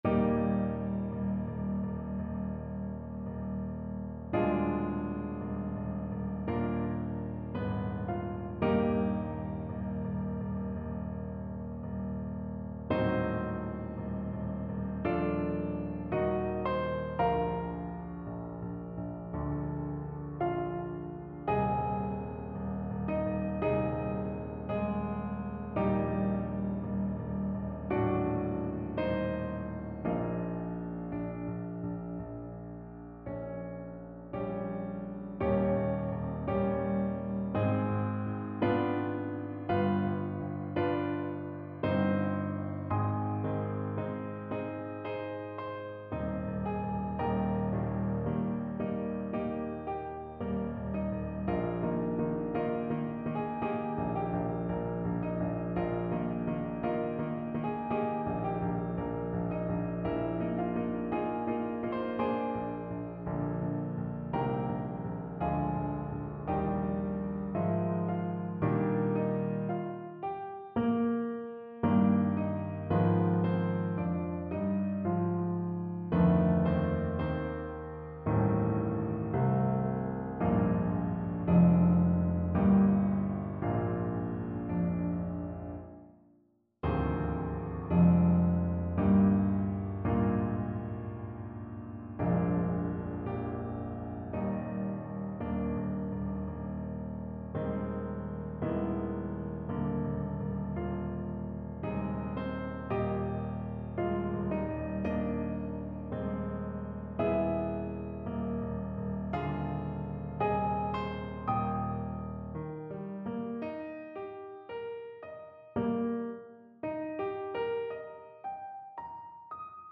Brahms, Johannes - Song of Destiny (Schicksalslied), Op.54 Free Sheet music for Choir (SATB)
Instrument: Choir
Style: Classical